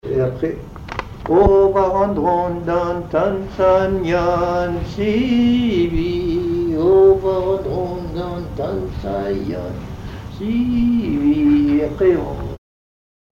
Chansons en breton
Pièce musicale inédite